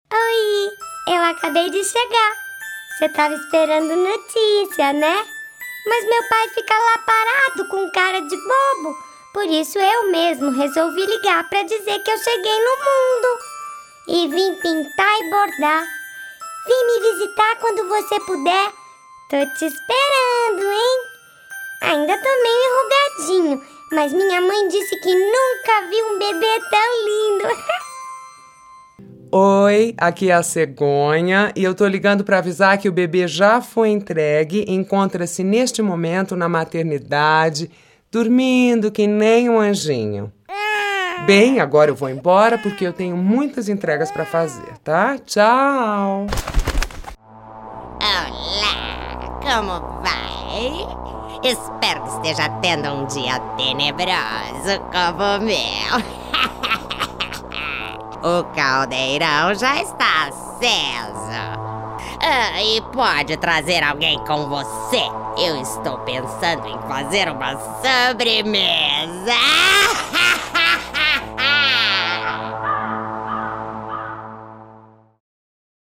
Feminino
Atriz - personagens caricatas